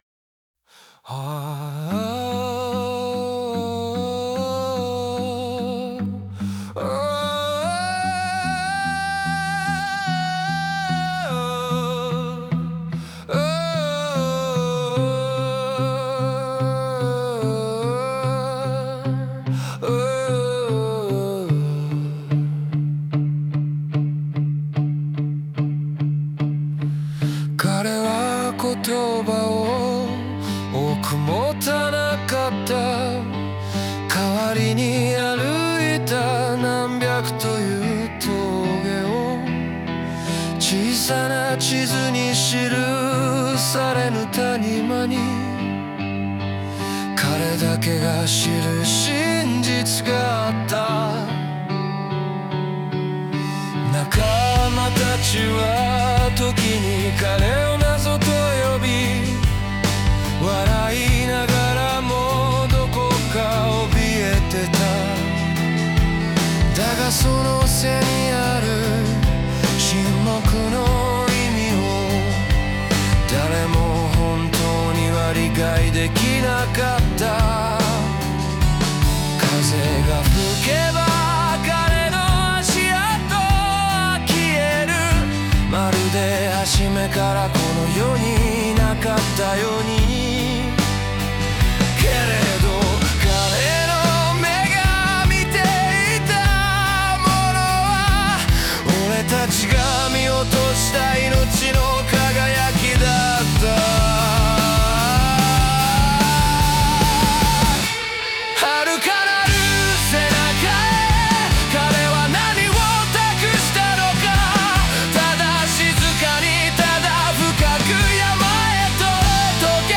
オリジナル曲♪
音楽の展開に合わせて、感情の高まりや内面の揺れを丁寧に表現し、聴く者に静かな問いかけを残す作品となっている。